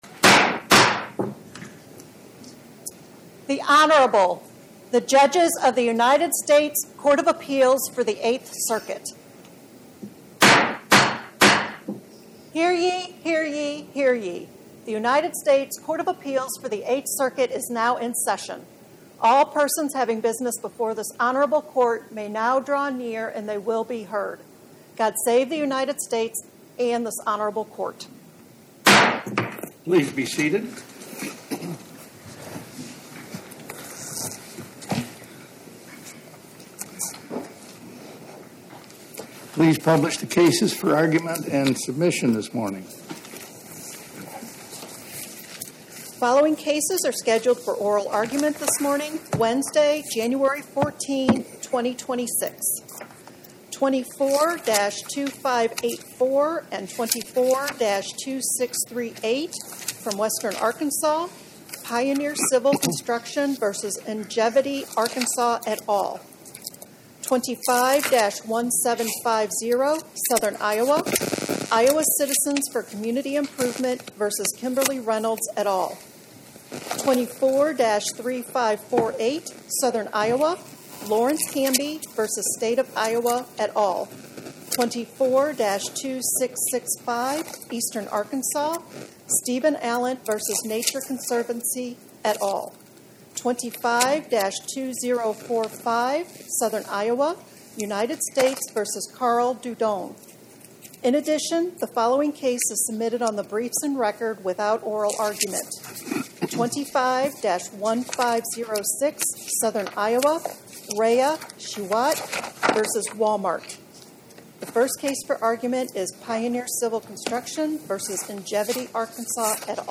Oral argument argued before the Eighth Circuit U.S. Court of Appeals on or about 01/14/2026